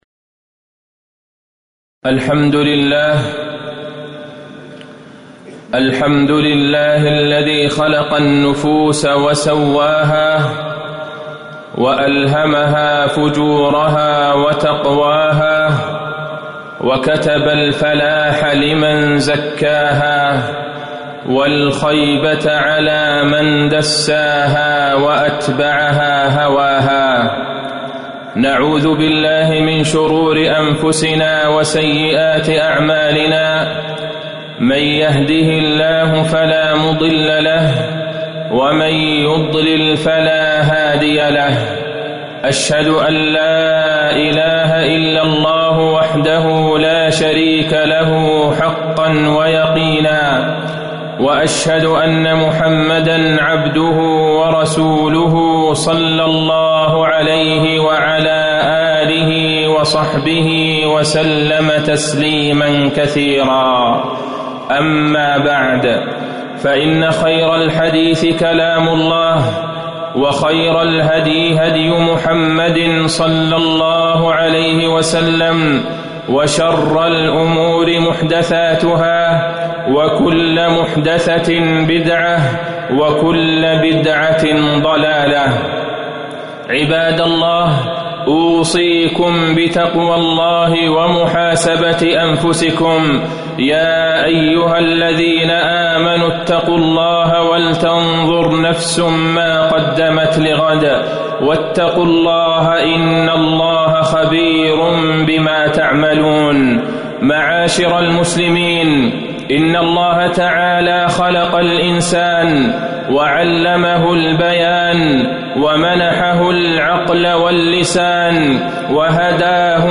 تاريخ النشر ٢٥ محرم ١٤٤٠ هـ المكان: المسجد النبوي الشيخ: فضيلة الشيخ د. عبدالله بن عبدالرحمن البعيجان فضيلة الشيخ د. عبدالله بن عبدالرحمن البعيجان تزكية النفوس